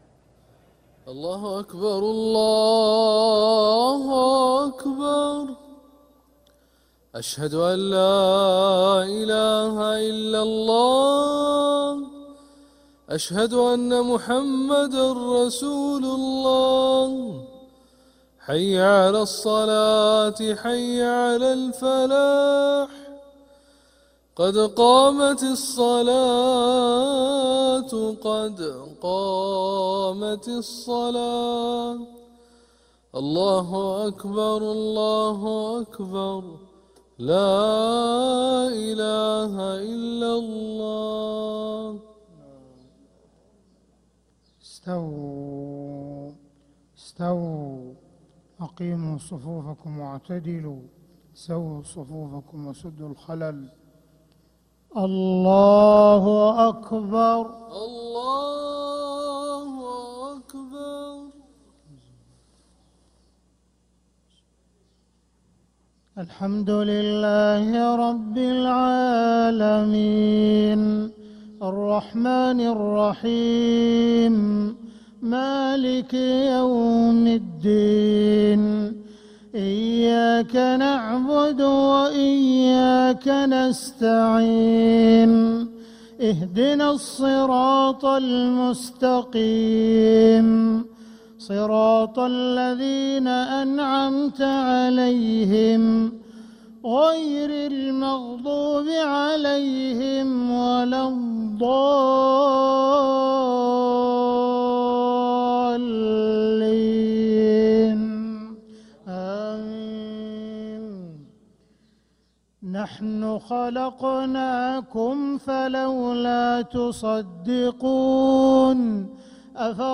Haramain Salaah Recordings: Makkah Fajr - 08th December 2025